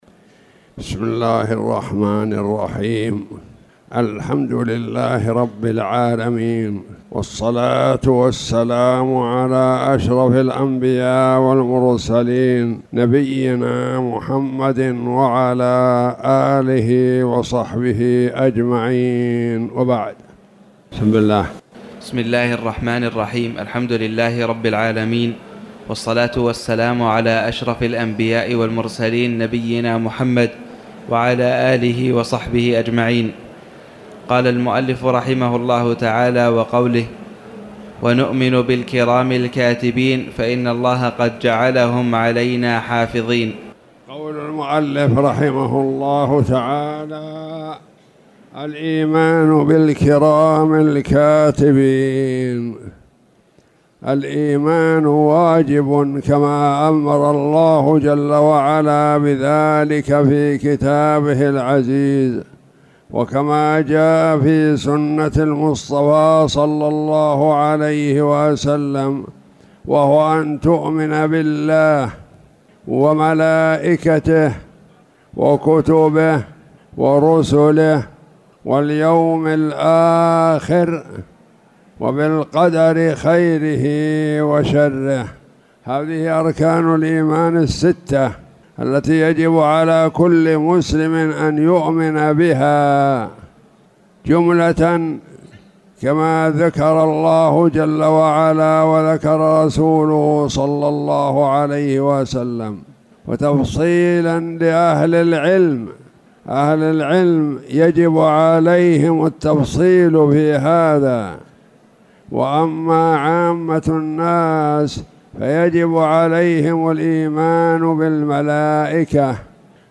تاريخ النشر ٢٠ شعبان ١٤٣٨ هـ المكان: المسجد الحرام الشيخ